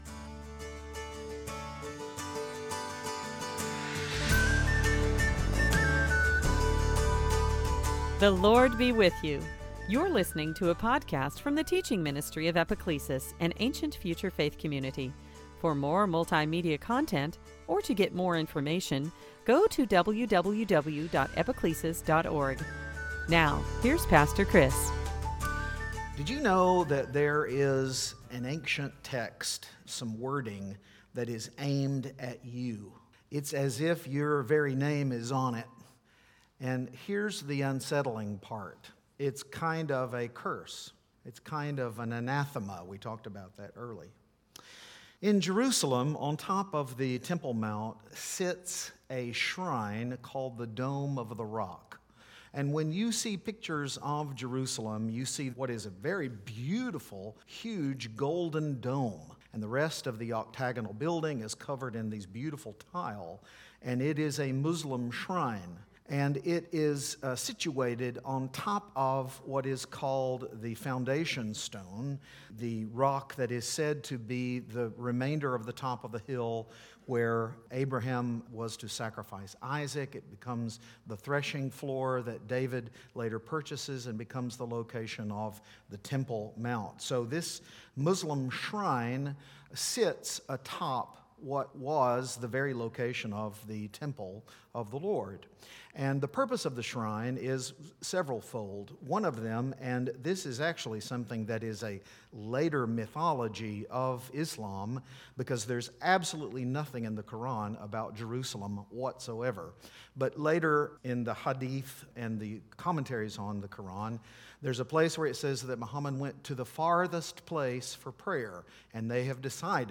Join us for a Scripture-rich teaching on the truth that God is One.